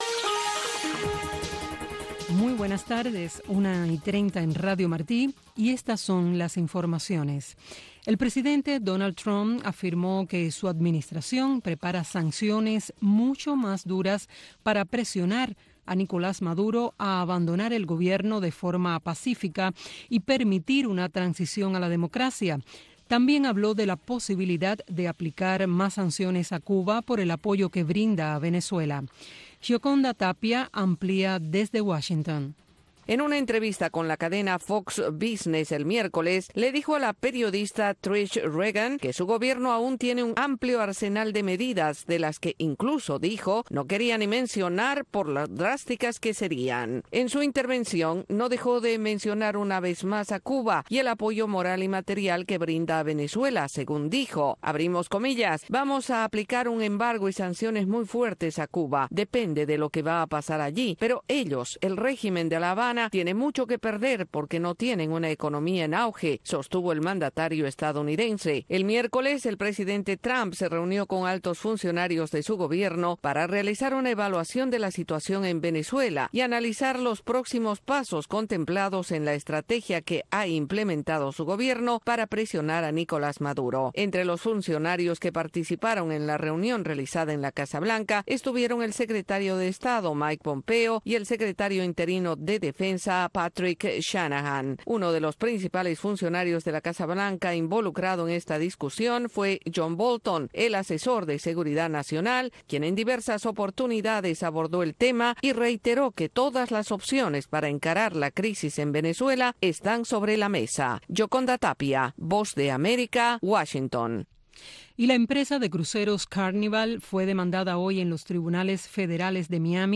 “Ventana a Miami”, un programa conducido por el director de la oficina de transmisiones hacia Cuba, Tomás Regalado, te invita a sintonizarnos de lunes a viernes a la 1:30 PM en Radio Martí. “Ventana a Miami” te presenta la historia de los cubanos que se han destacado en el exilio para que tú los conozcas.